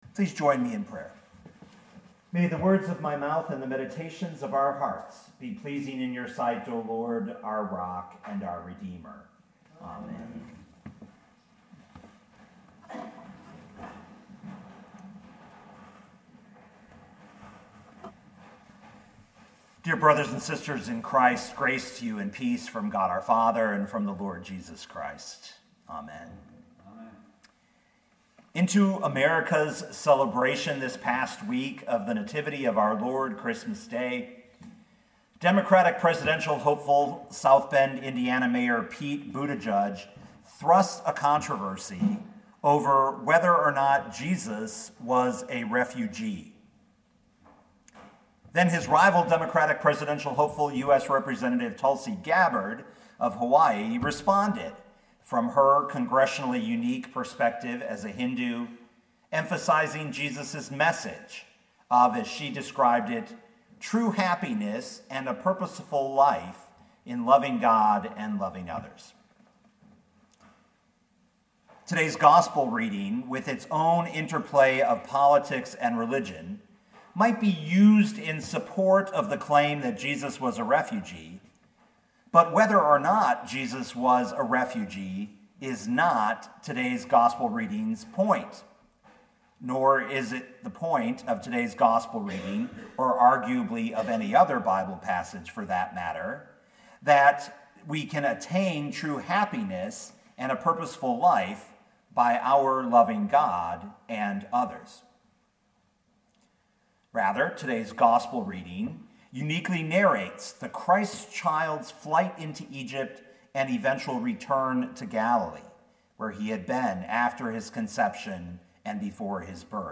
2019 Matthew 2:13-23 Listen to the sermon with the player below, or, download the audio.